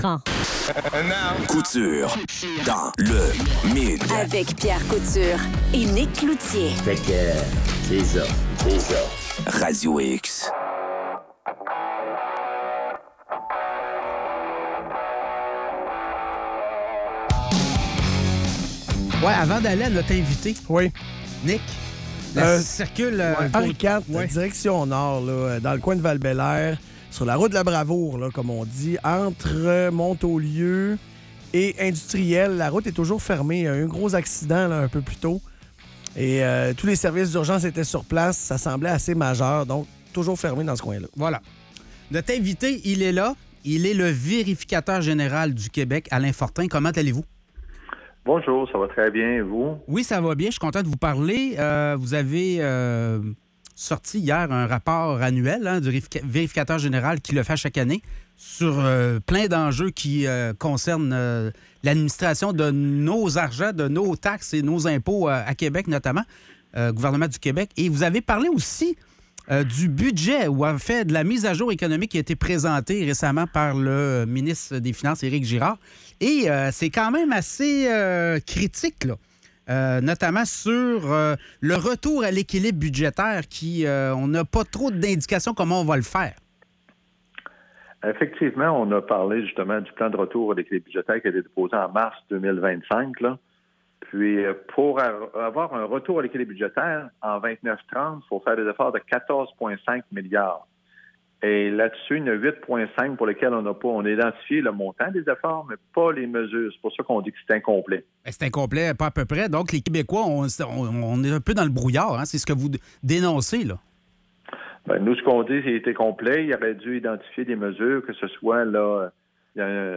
Entrevue avec Alain Fortin, vérificateur général du Québec